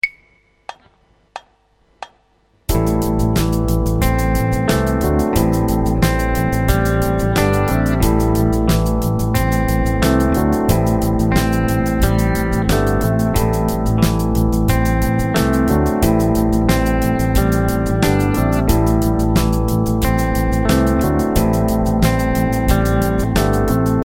This example base on an A minor arpeggio sounds clearer with a clean guitar tone. The slower 8th notes of the arpeggio create a nice rhythmic contrast to the faster 16th note rhythms of the drums.